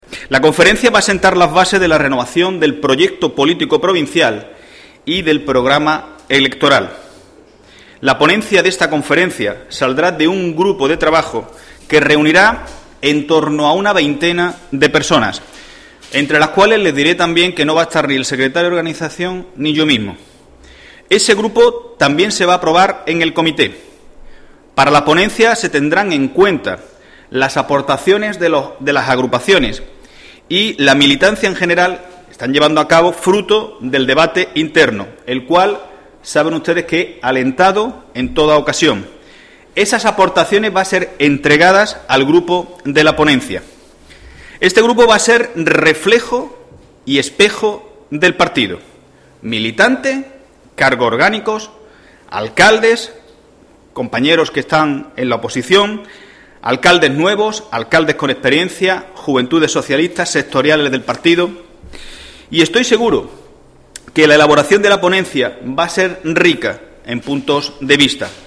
Les adelanto que será una conferencia con amplios cauces de participación ciudadana", ha asegurado Heredia en rueda de prensa.